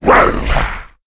c_saurok_atk1.wav